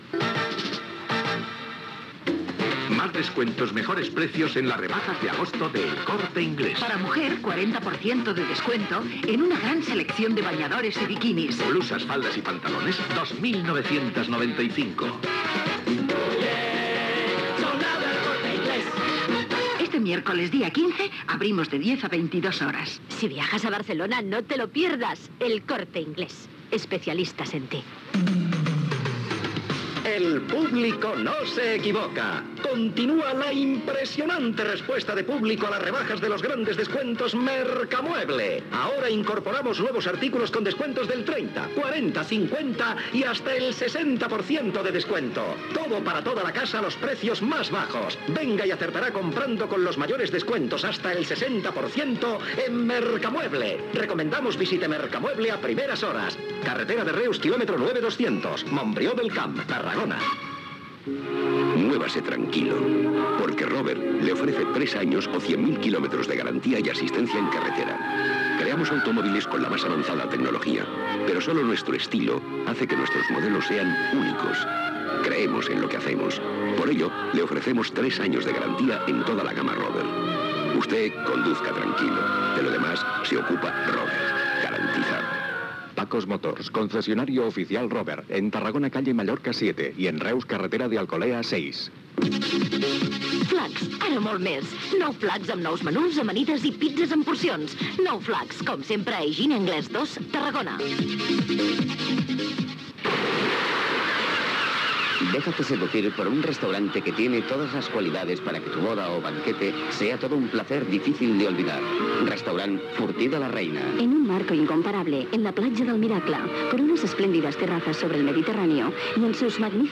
Publicitat i sintonia de l'emissora